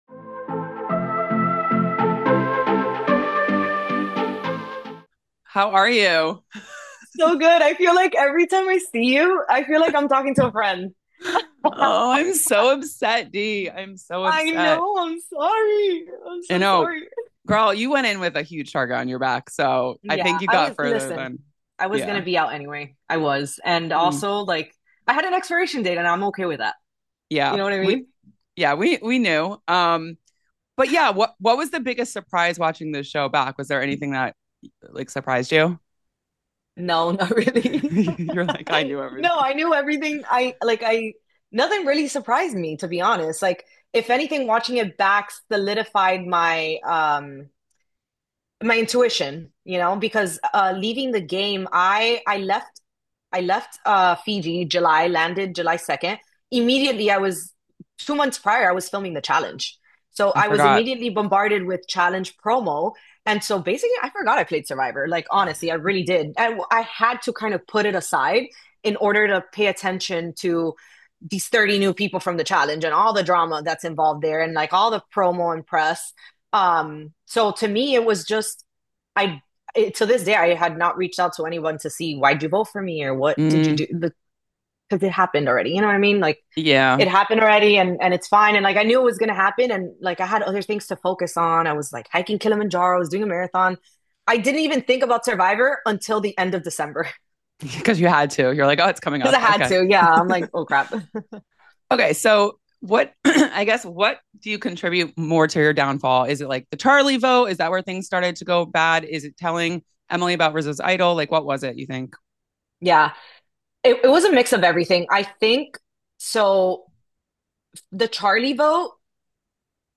Survivor 50 Exit Interview: 11th Player Voted Out
Former Survivor winner Dee Valladares joins The Exclusive after her exit from Season 50 to break down where things really went wrong—from the “mix of everything” that unraveled her game to the one move she calls her biggest regret: revealing Rizo’s idol.